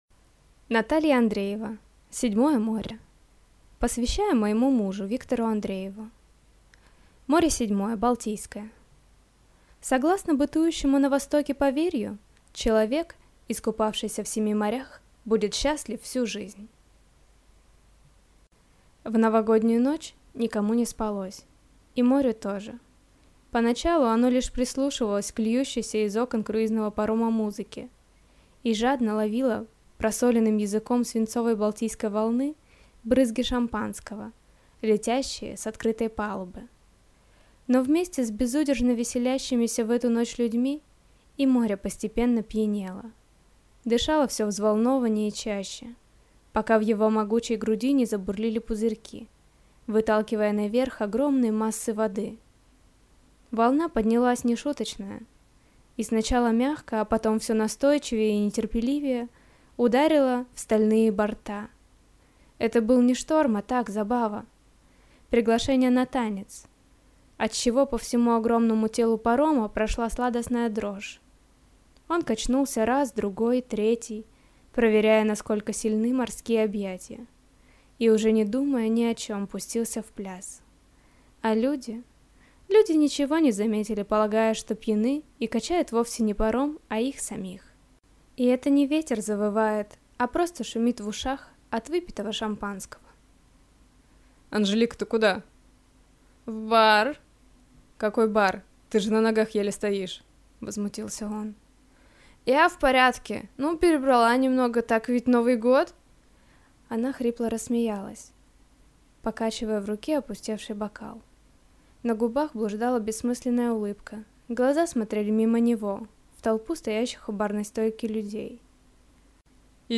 Аудиокнига Седьмое море | Библиотека аудиокниг